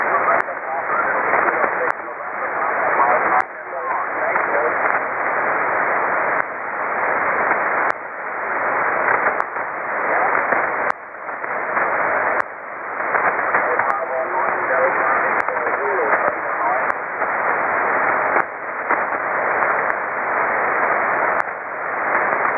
17:00z      17cw up S3 mur dense de 7 kHz, faisable.